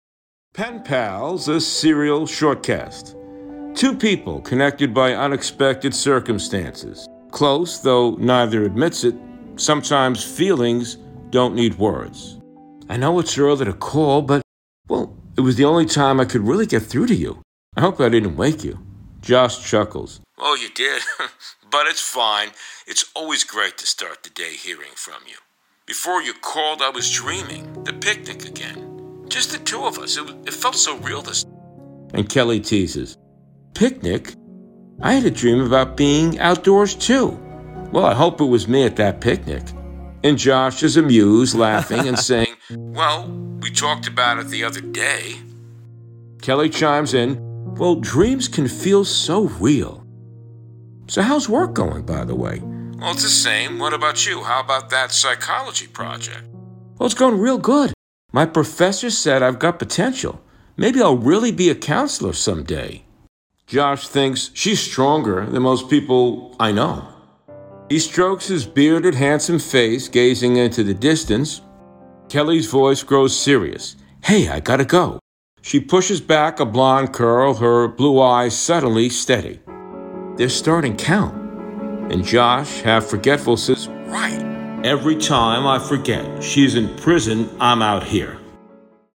Kelly calls Josh early in the morning, waking him up with her cheerful voice.